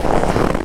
HauntedBloodlines/STEPS Snow, Walk 05.wav at main
STEPS Snow, Walk 05.wav